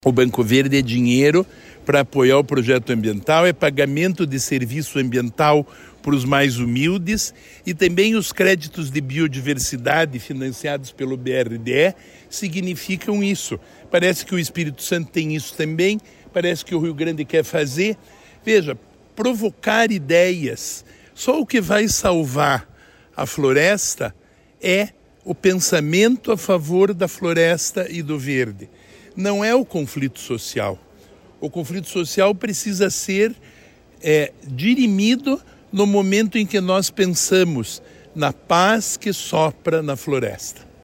Sonora do secretário do Desenvolvimento Sustentável, Rafael Greca, sobre o Banco Verde